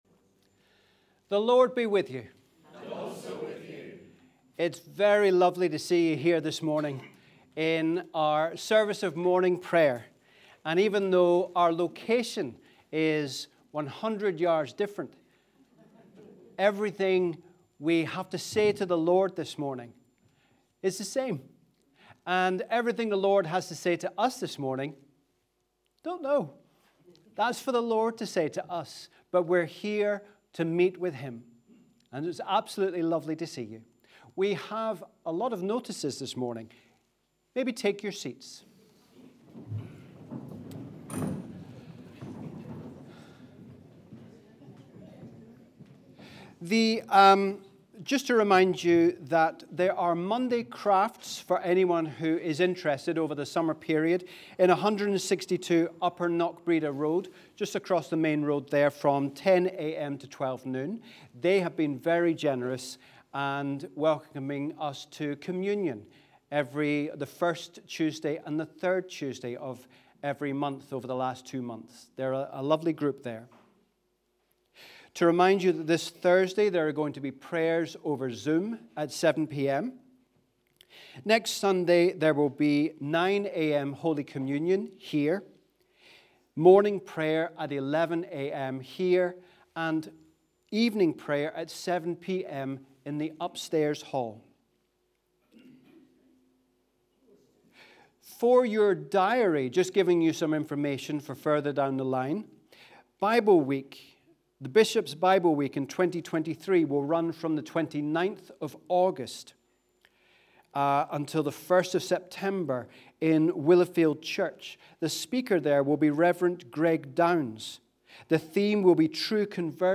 Wherever you are, we welcome you to our service of Morning Prayer on the 11th Sunday after Trinity.